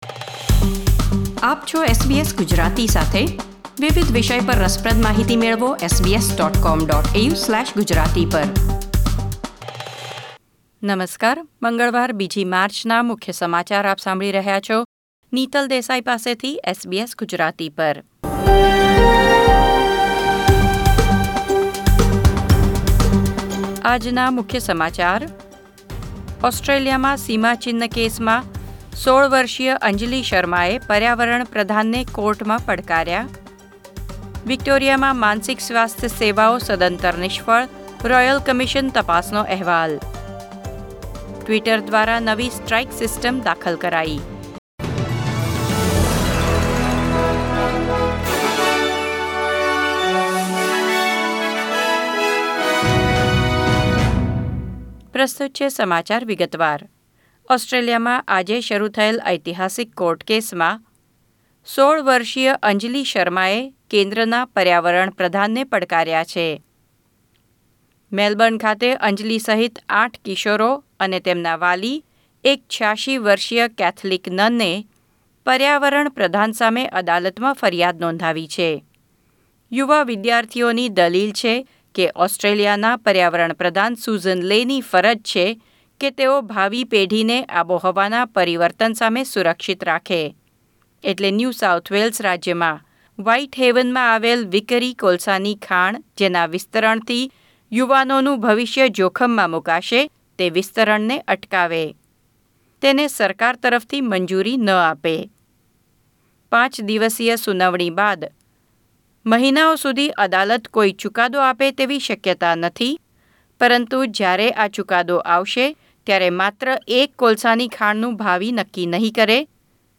SBS Gujarati News Bulletin 2 March 2021